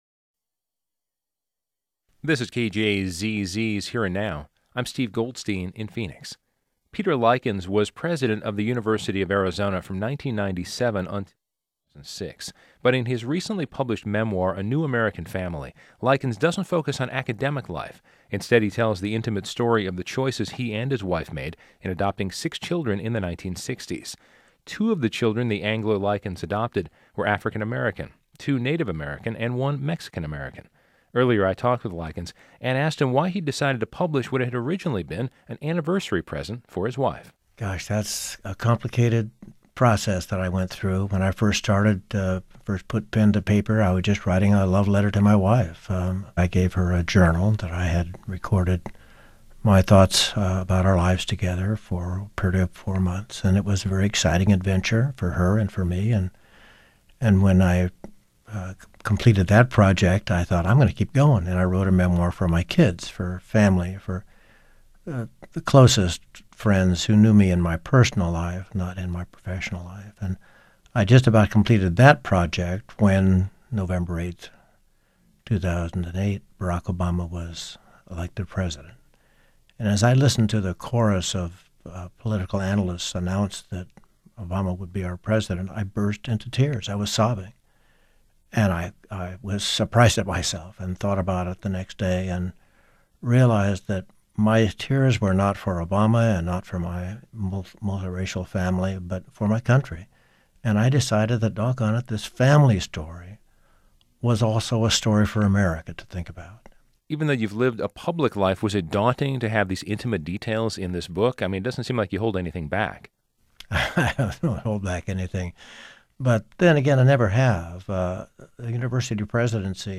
Radio Interview  |  TV Interview  |  Family Photos  |  New American Family Home